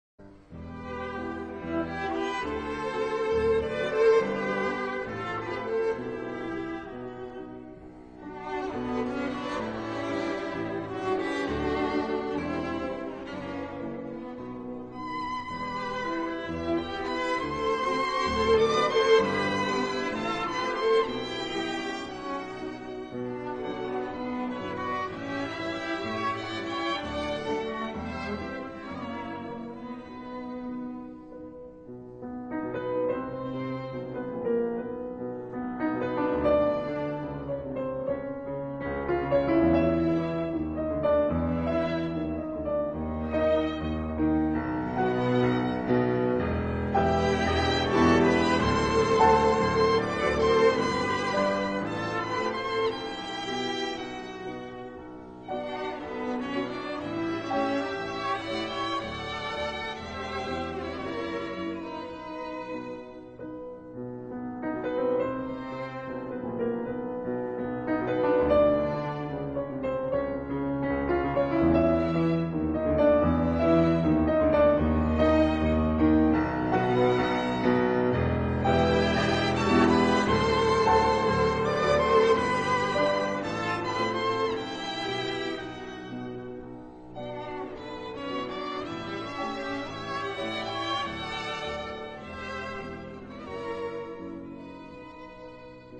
* Johannes Brahms – Quatuor en Sol Mineur Op. 25